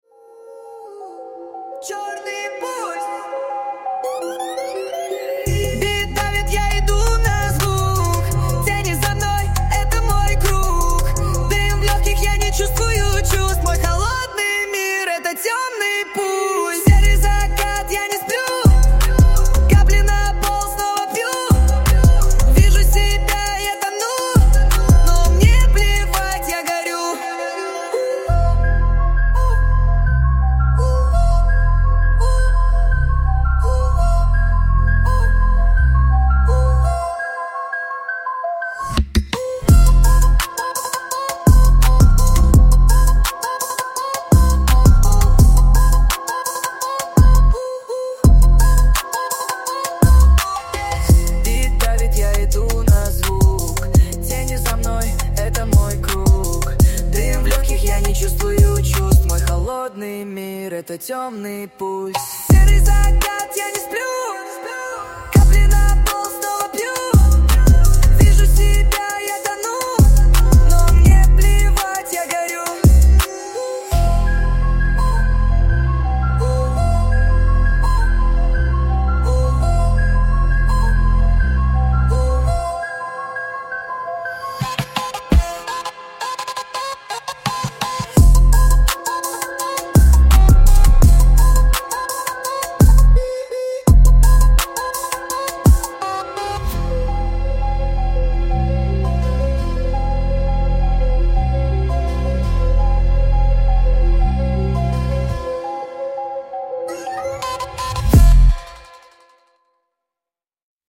Жанр: Hip Hop